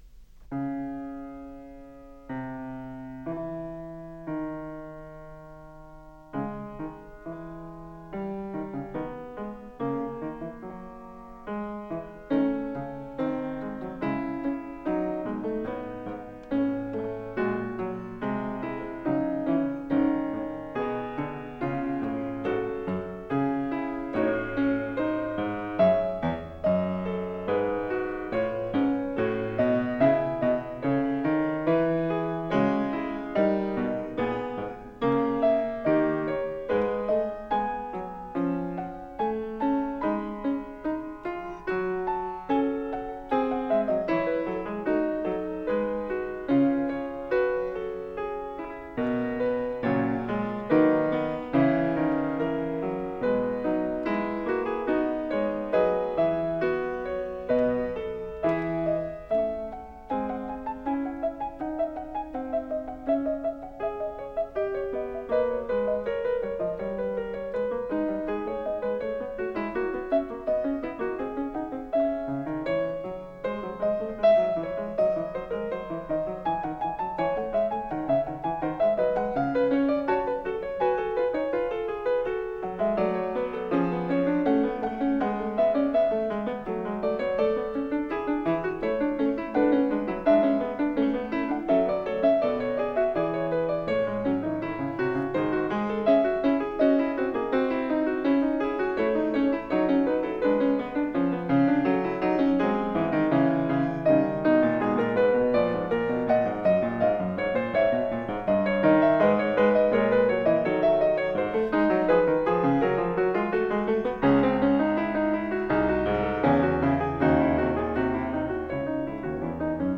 08 Wtc Vol I, Fugue No 4 In C Sharp Minor, Bwv849